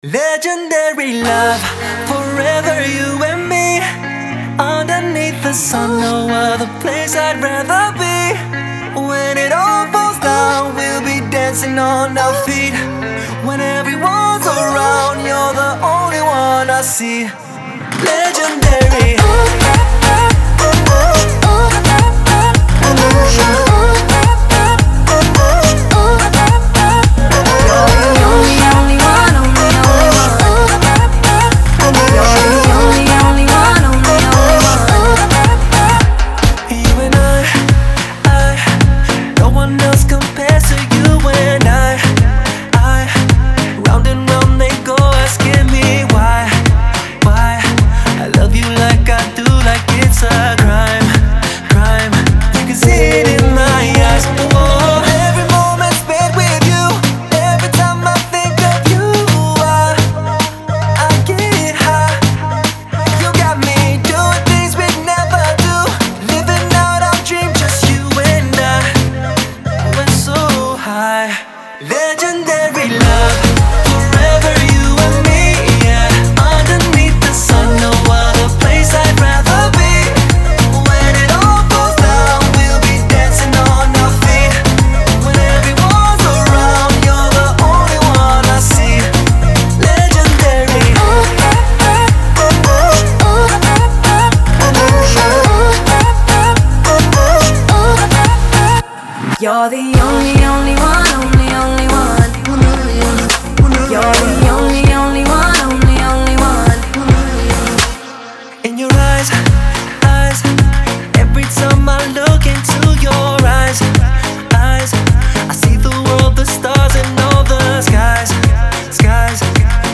Новинки 2016 Поп Музыка